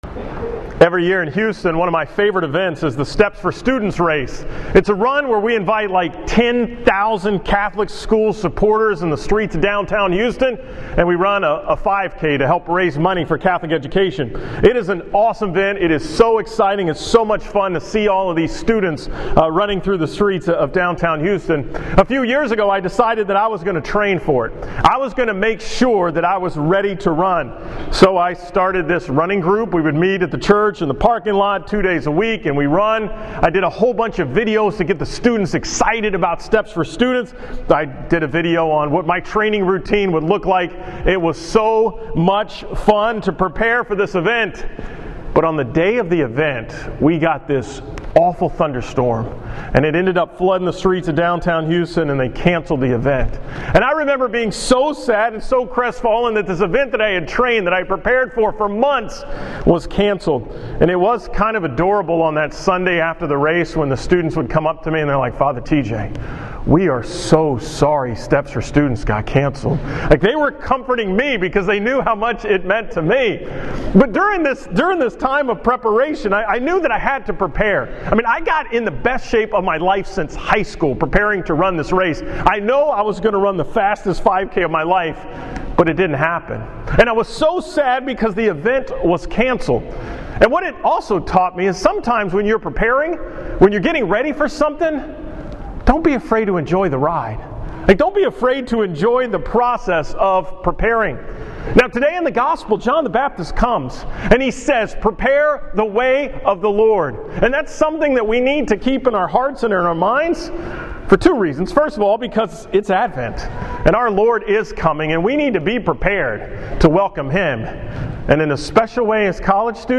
From St. Mary's in College Station on the Campus of Texas A&M on Sunday, December 6, 2015